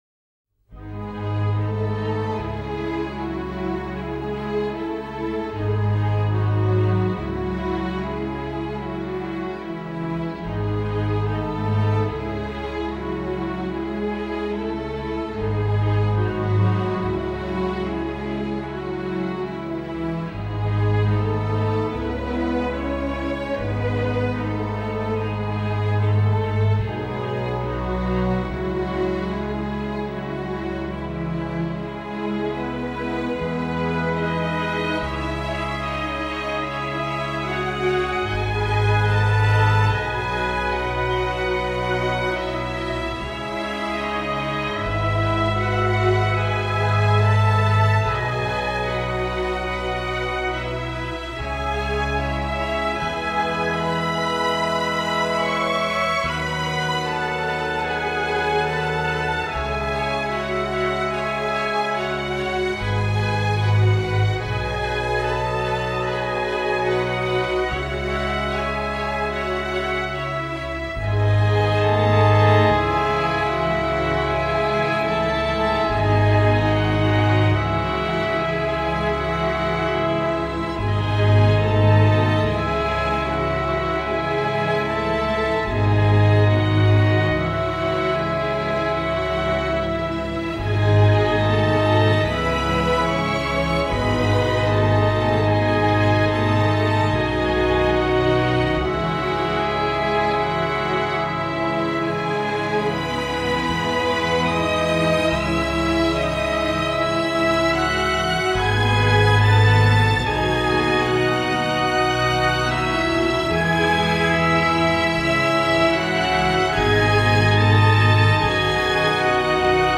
Soundtrack, Post-Minimalist